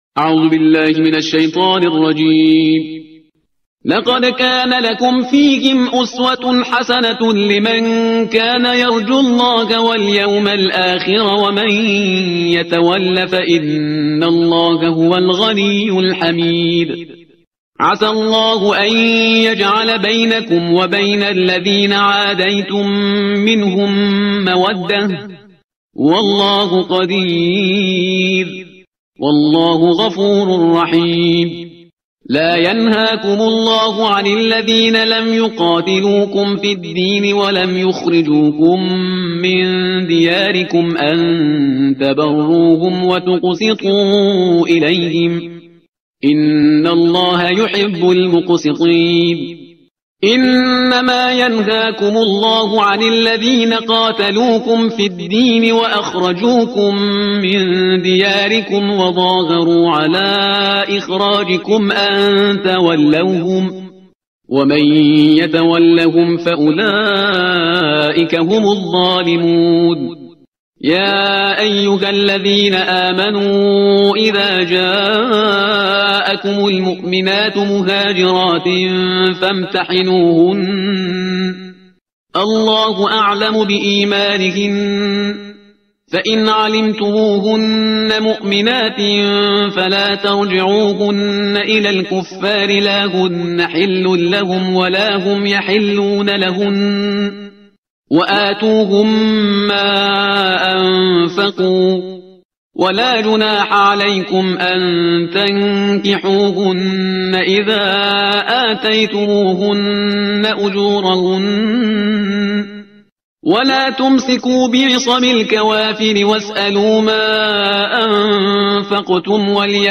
ترتیل صفحه 550 قرآن